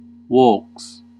Ääntäminen
IPA : /vɔɪs/